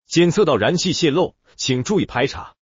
alarm1.mp3